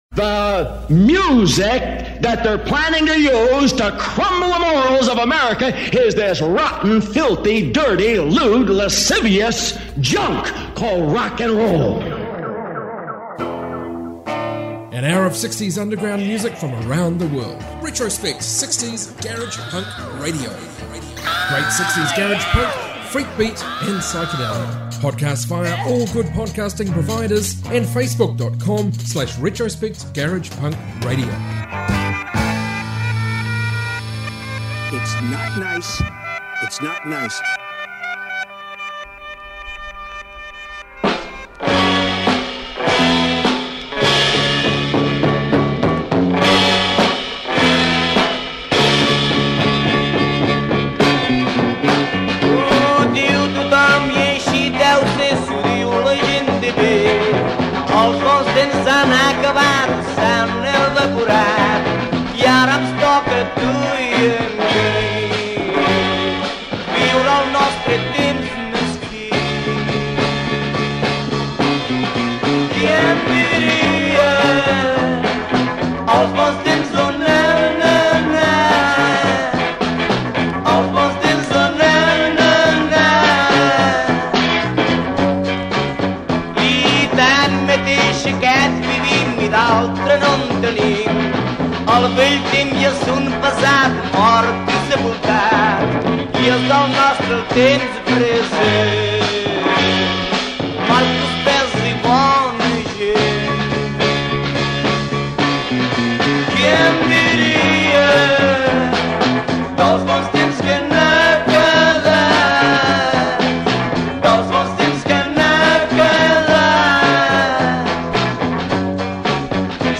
60s garage rock podcast